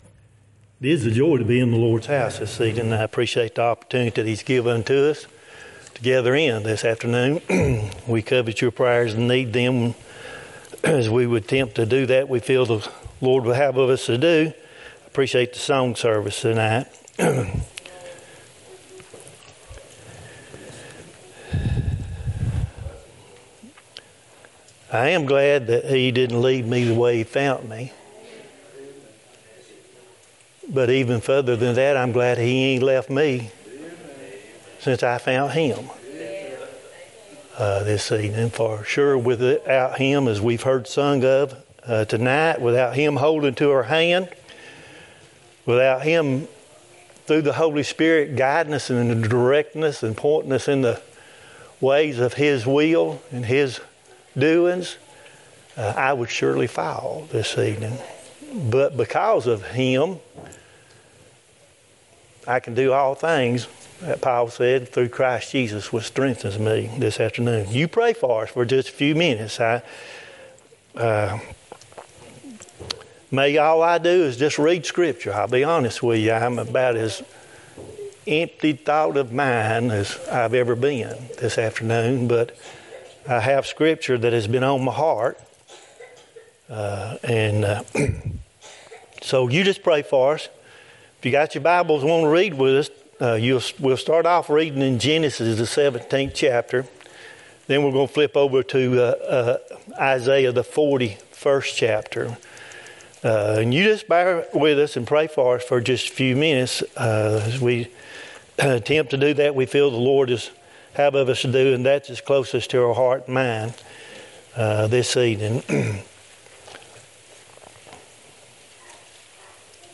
Genesis 17:1-9 Isaiah 41:10,13 Service Type: Wednesday night https